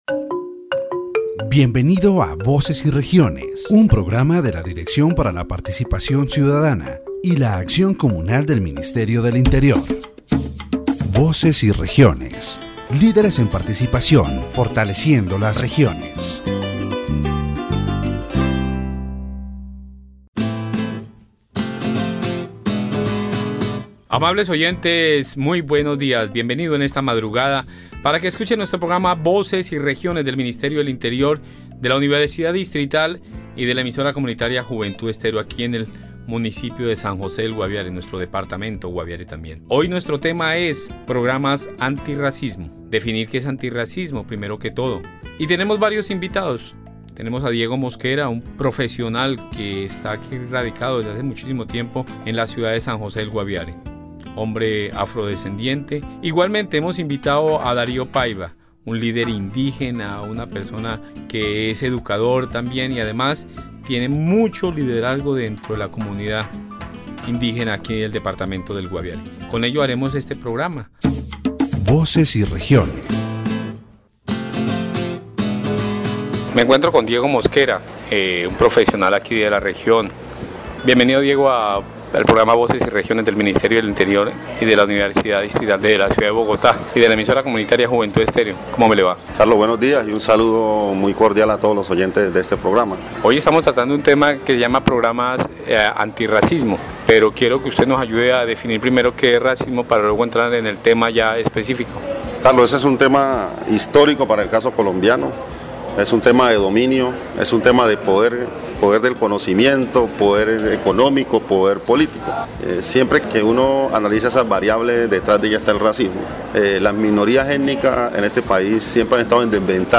In this section of the Voces y Regiones program, the discussion focuses on anti-racism programs and their impact on Colombian society. The interviewee highlights the difficult situation of ethnic minorities, particularly those living in poverty, emphasizing that education is the key tool for eradicating racism.